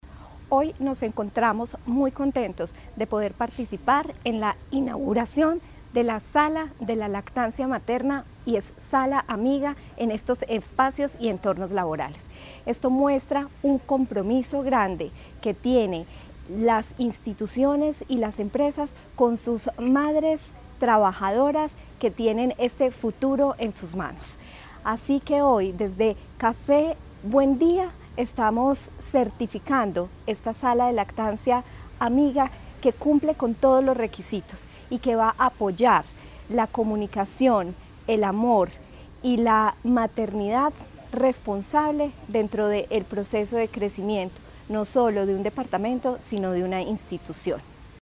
Durante el acto de entrega, Natalia Castaño Díaz, directora de la DTSC, subrayó la importancia de que las instituciones se comprometan con prácticas que favorezcan la maternidad responsable.
Natalia Castaño Díaz, directora de la DTSC.